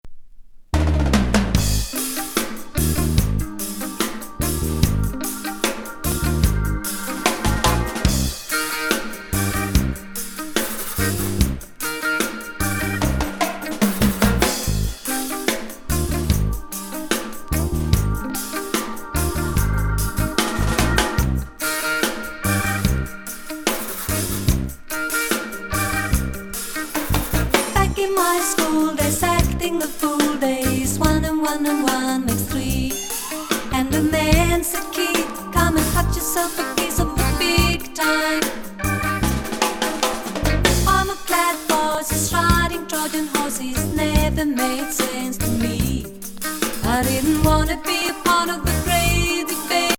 ＆メロウな好レゲエ・ポップ